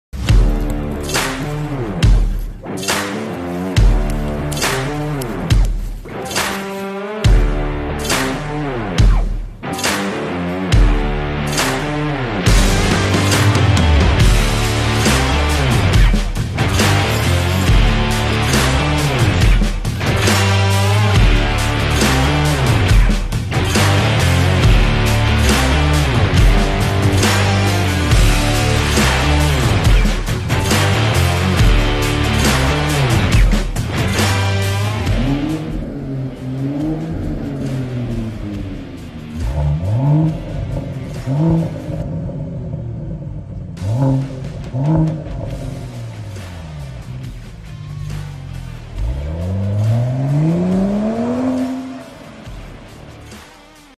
Mercedes Benz S480 W223 Maybach Exhaust Sound Effects Free Download
Mercedes-Benz S480 W223 Maybach Exhaust